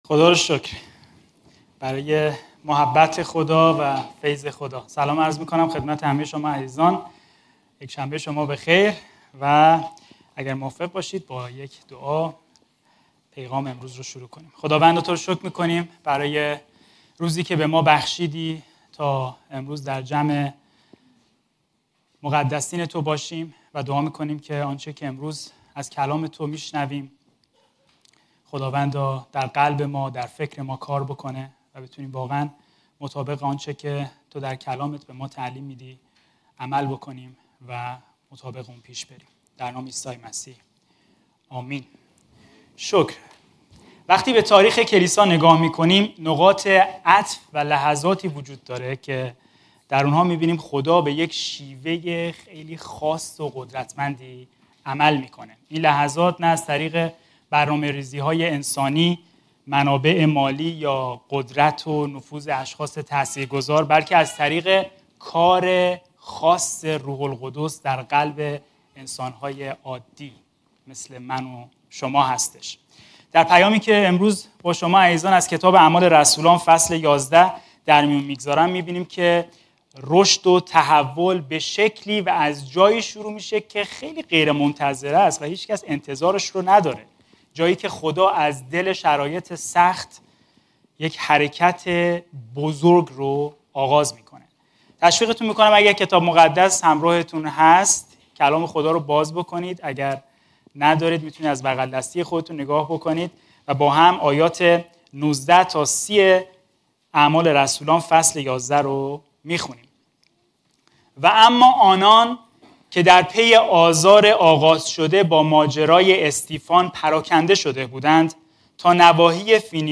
موعظه‌ها